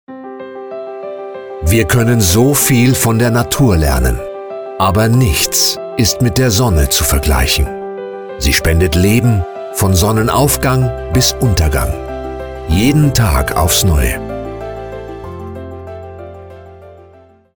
Commercial BEKO